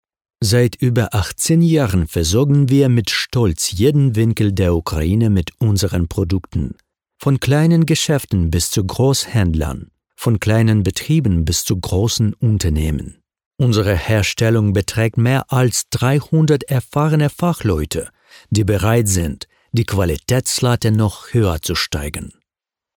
Native speaker Male 30-50 lat
Narracja w języku niemieckim